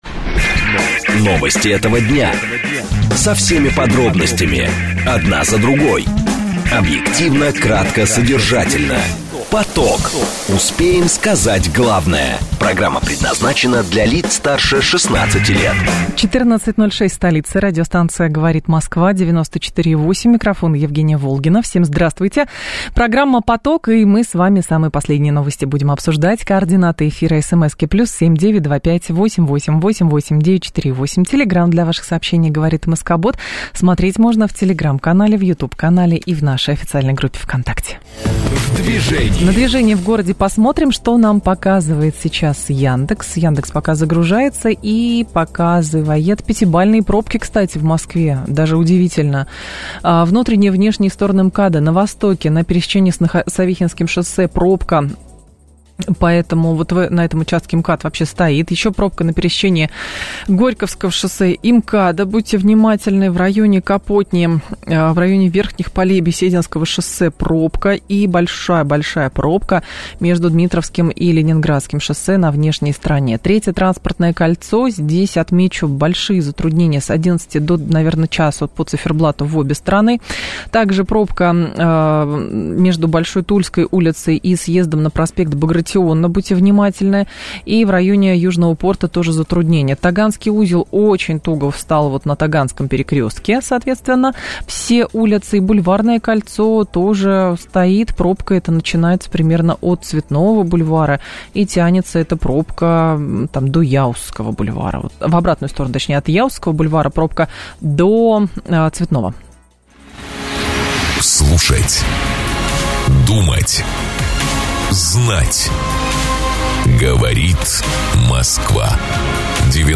В прямом эфире радио "Говорит Москва" рассказал о том, почему известный инфоцыган Аяз Шабутдинов не сможет научить вас тому, как создать успешный бизнес, а также о том, почему этому не смог бы научить и более умный и образованный человек и/или успешный бизнесмен. А еще порекомендовал пару полезных книг.